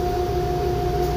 power_generators_gearbox_running.1.ogg